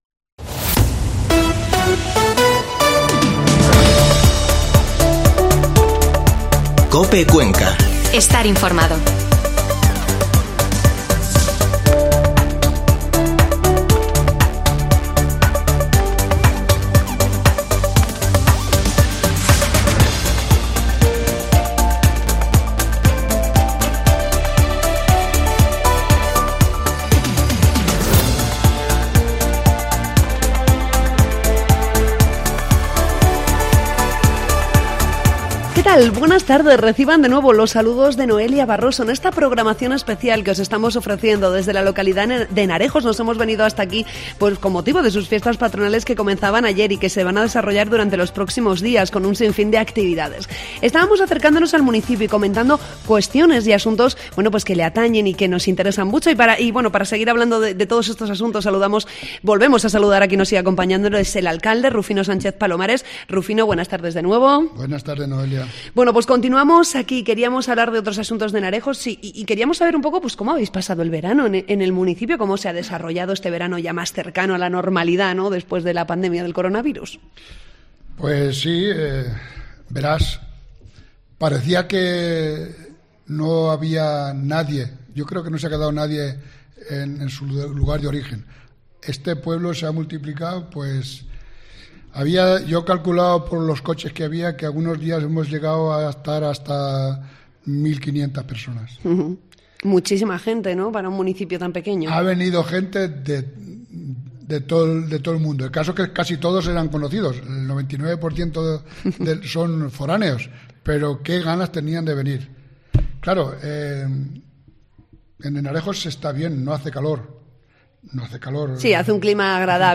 Mediodía en COPE Cuenca desde Henarejos